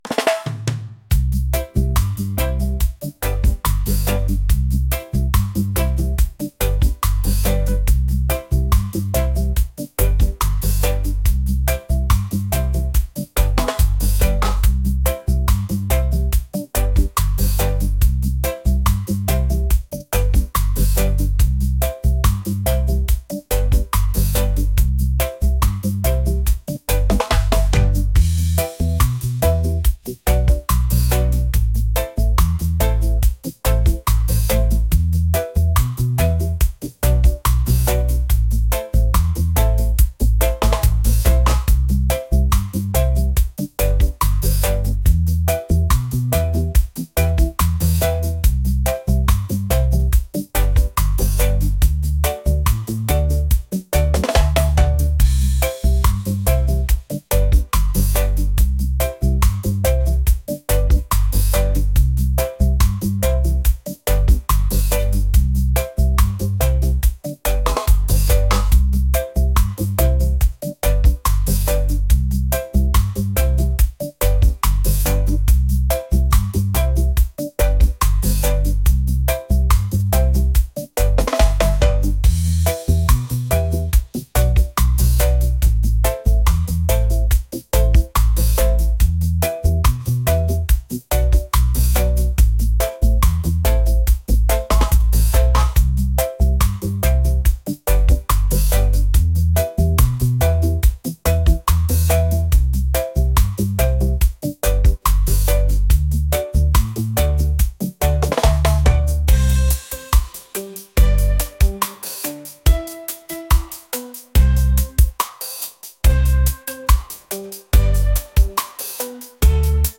reggae | lofi & chill beats | lounge